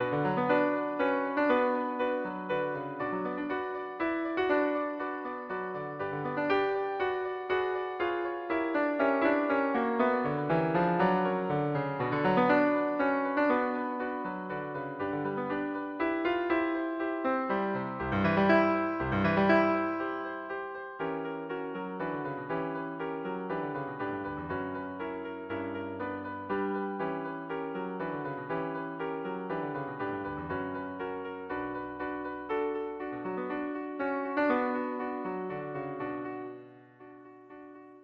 3/4 (View more 3/4 Music)
Lento assai
Piano  (View more Intermediate Piano Music)
Classical (View more Classical Piano Music)